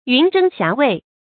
云蒸霞蔚 yún zhēng xiá wèi 成语解释 蒸：升腾；蔚：聚集。
成语繁体 雲蒸霞蔚 成语简拼 yzxw 成语注音 ㄧㄨㄣˊ ㄓㄥ ㄒㄧㄚˊ ㄨㄟˋ 常用程度 常用成语 感情色彩 中性成语 成语用法 联合式；作谓语、定语、状语；含褒义 成语结构 联合式成语 产生年代 近代成语 成语正音 蔚：不能读作“yù”。